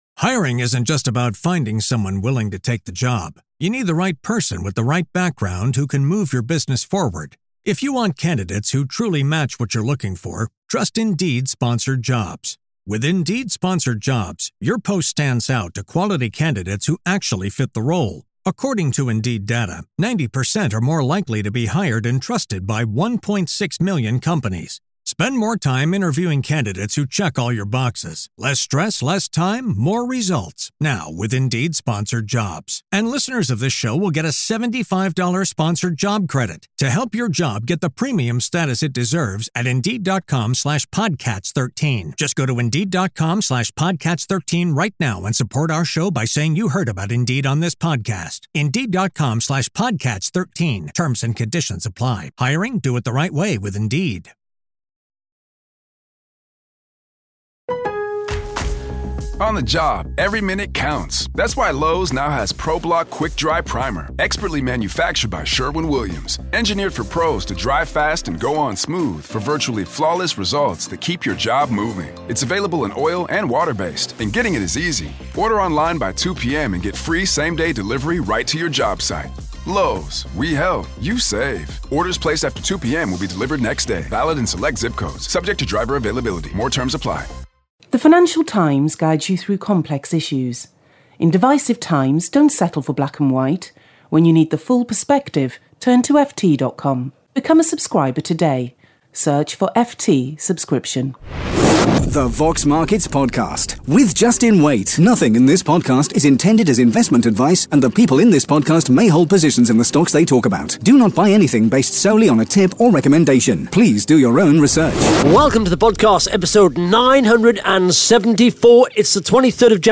(Interview starts at 1 minute 17 seconds)